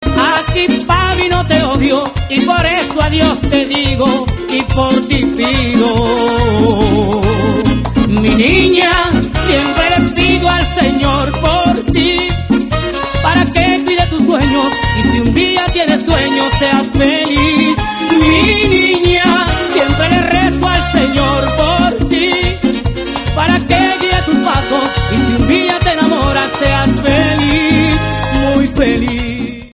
• Category Latin and Salsa